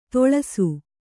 ♪ toḷasu